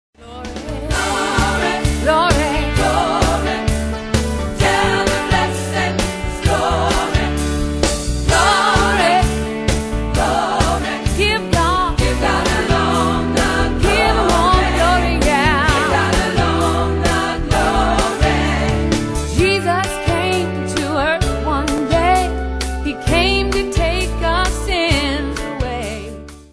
gospel choir song
soulful voice is featured on this song